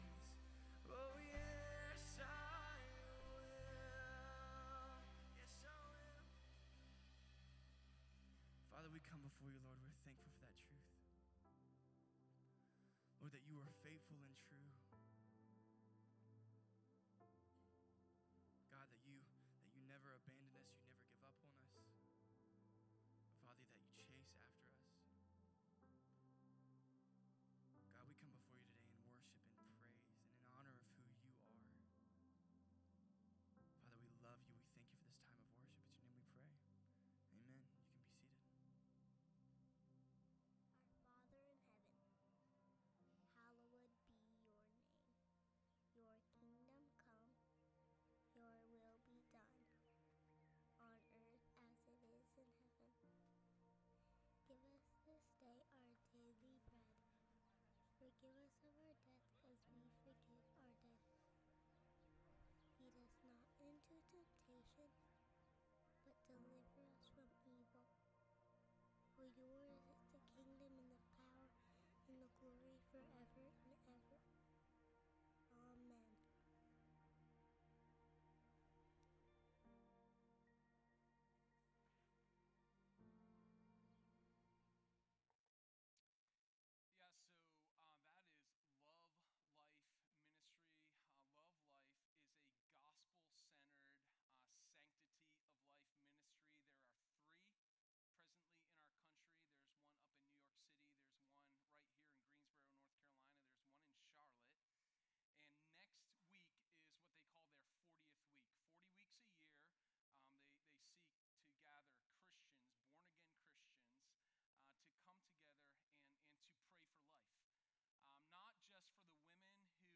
Sermon1115_Pursue-Your-Hope-in-the-Redeemer.m4a